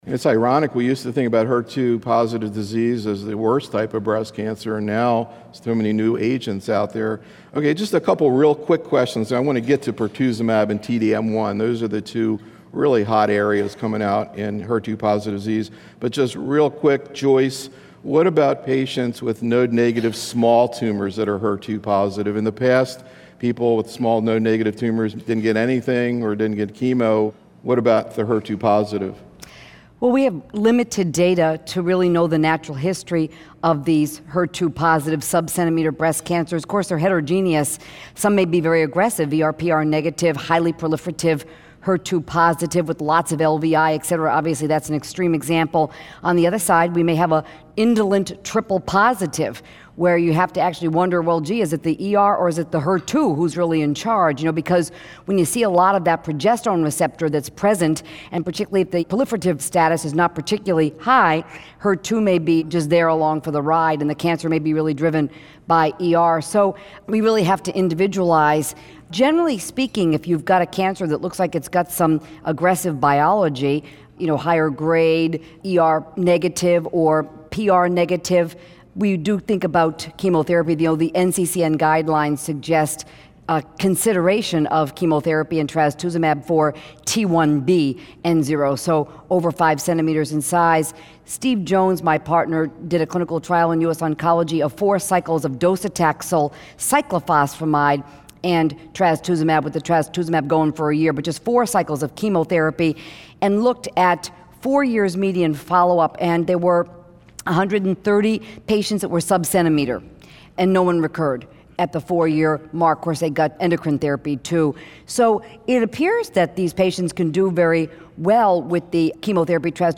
In these audio proceedings from a symposium held in conjunction with the Oncology Nursing Society's 2012 Annual Congress, the invited oncology nursing professionals participating as part of our faculty panel present actual patient cases from their practices, setting the stage for faculty discussion of optimal therapeutic and supportive care strategies in breast cancer.